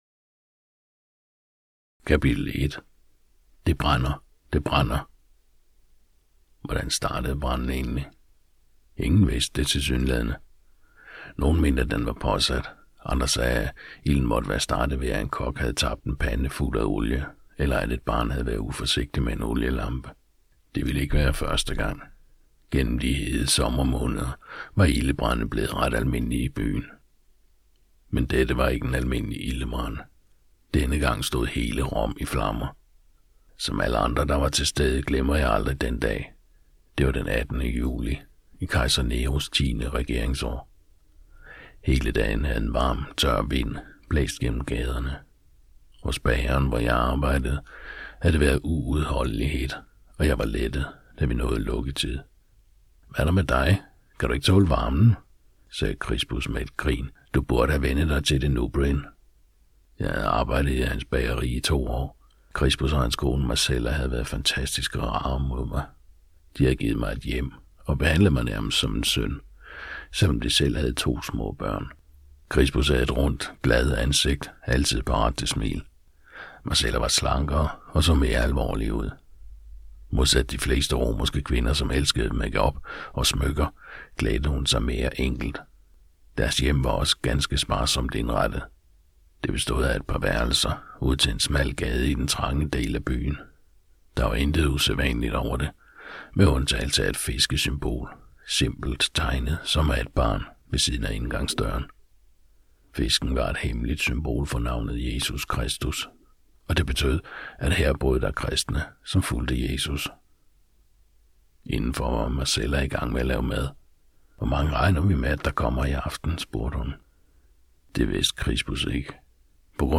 Hør et uddrag af Rom i flammer Rom i flammer Format MP3 Forfatter Kathy Lee Bog Lydbog 99,95 kr.